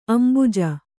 ♪ ambuja